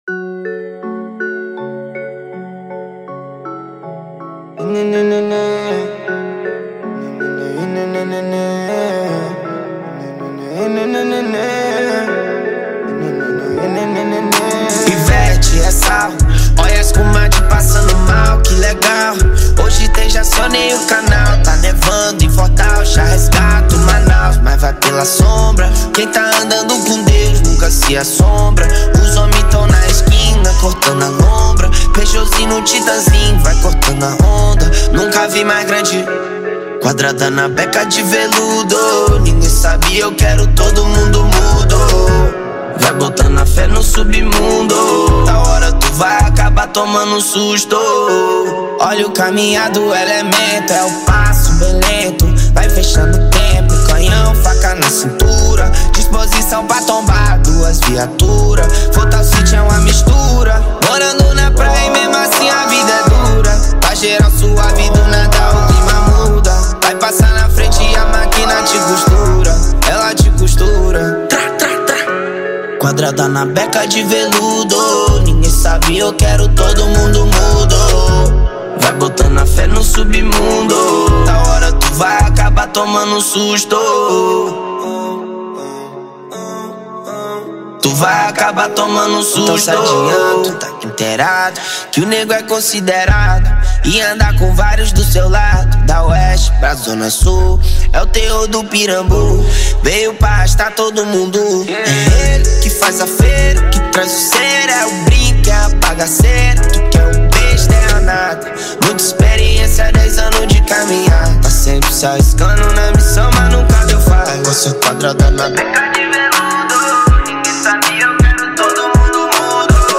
2024-04-08 19:04:55 Gênero: Trap Views